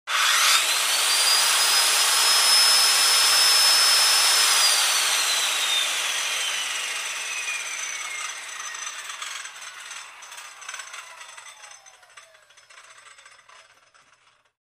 in_circularsaw_run_01_hpx
Circular saw cuts wood as blade spins. Tools, Hand Wood, Sawing Saw, Circular